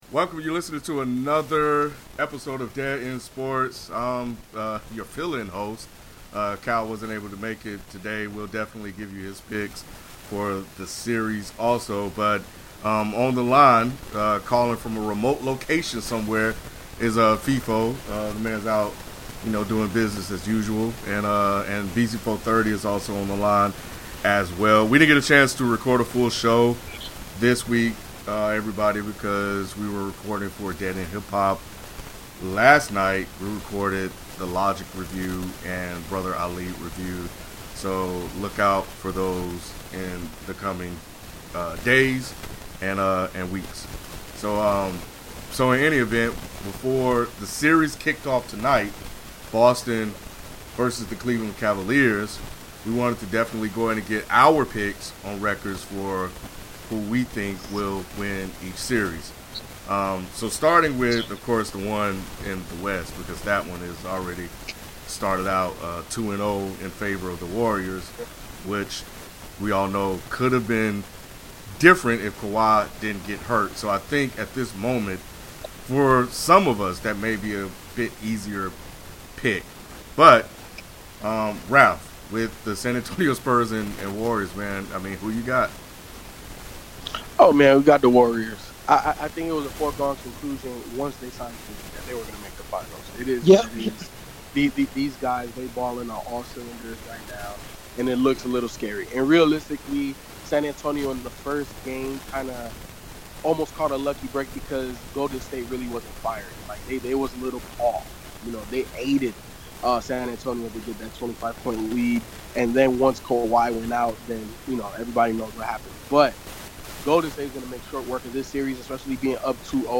*Please note there is a hissing noise in the recording.